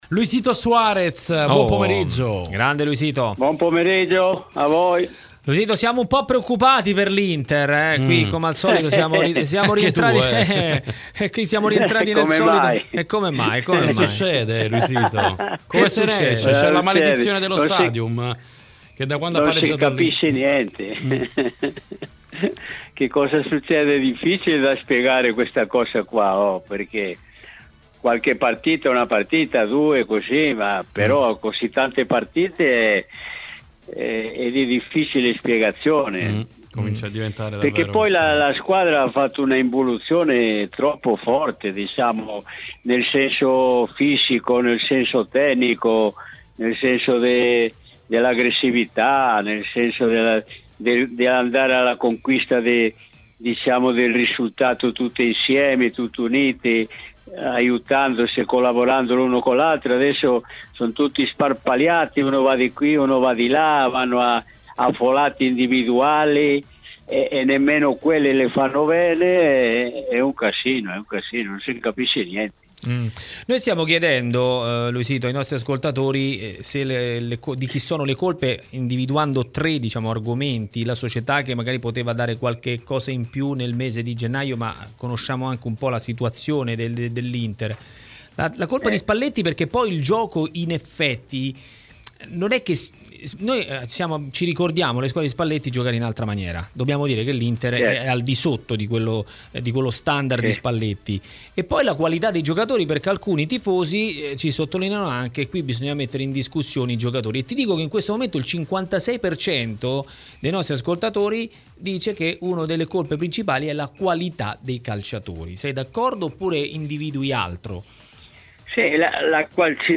Per analizzare a fondo la crisi dell'Inter di Spalletti, su RMC Sport è intervenuto l'ex giocatore e allenatore nerazzurro Luis Suárez.
intervistato